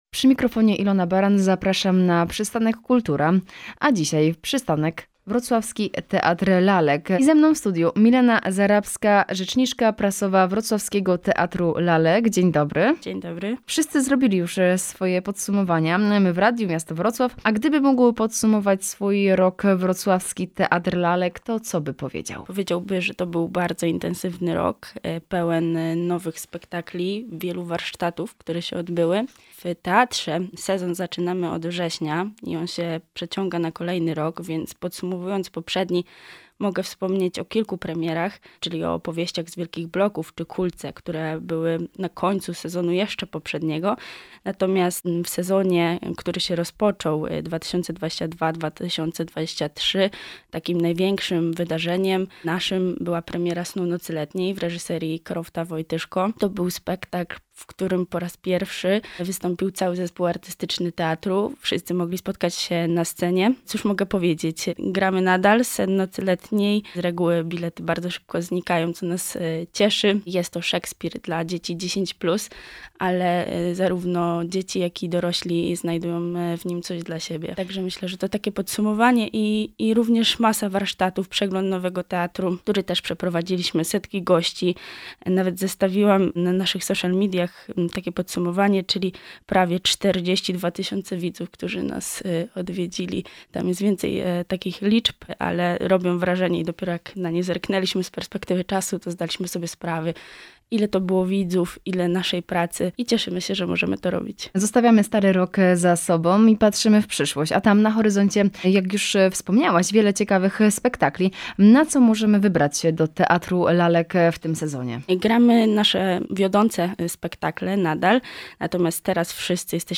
Cała rozmowa: https